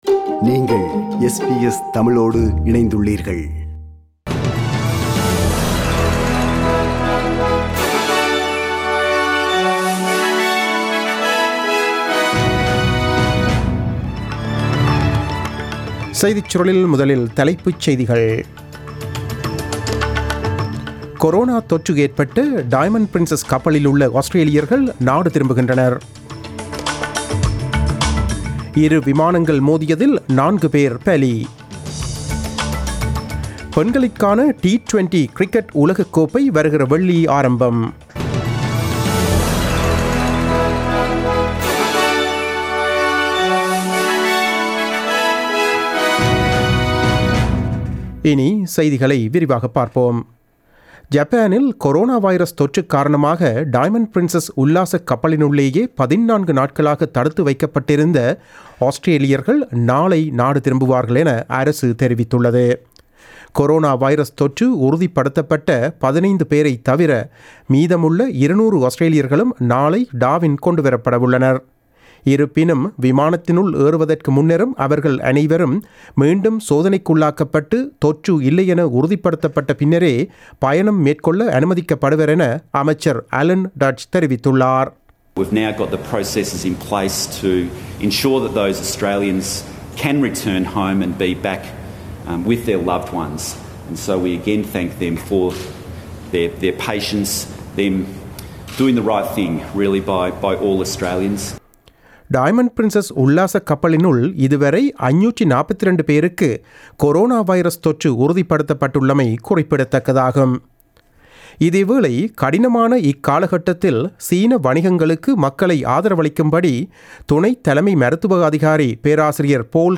நமது SBS தமிழ் ஒலிபரப்பில் இன்று புதன்கிழமை (19 February 2020) இரவு 8 மணிக்கு ஒலித்த ஆஸ்திரேலியா குறித்த செய்திகள்.